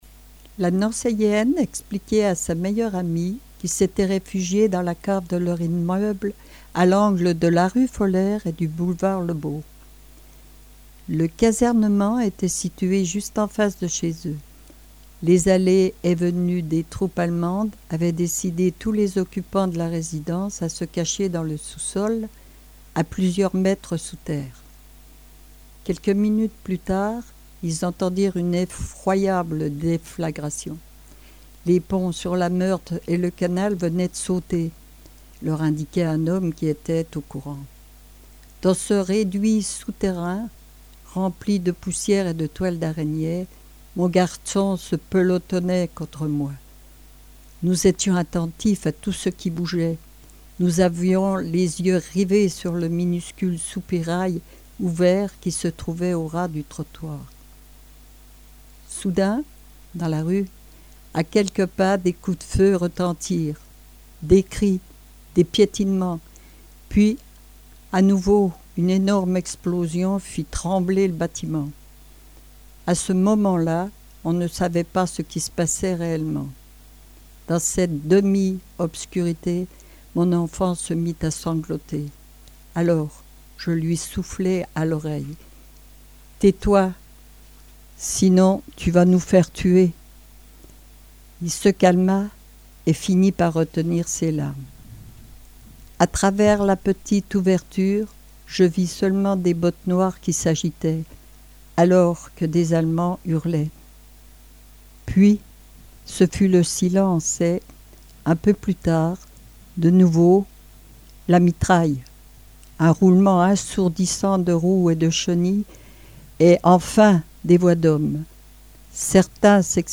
Une première pour cette lecture à haute voix, mais bon, j'ai osé...